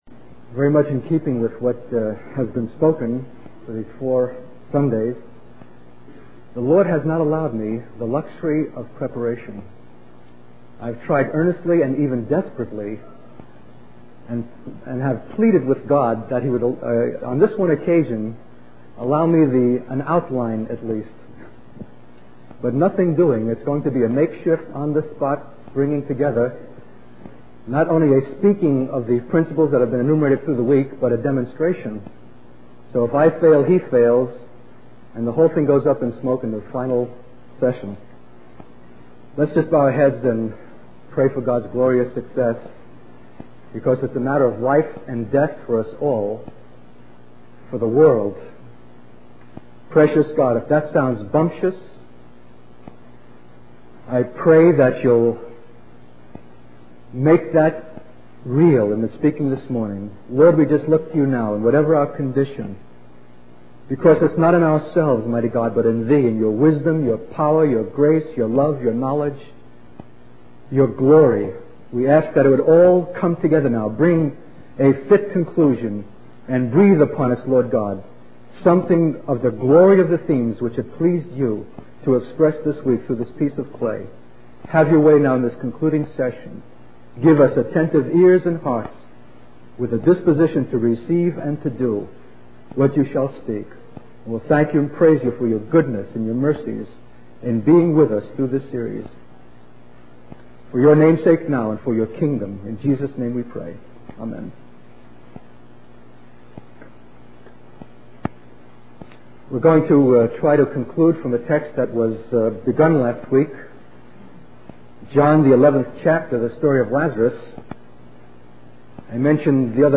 In this sermon, the speaker expresses his frustration and disappointment at not being able to prepare a proper outline for his sermon. He talks about the feeling of being bound and unable to move or speak, comparing it to the story of Lazarus being in the grave for four days.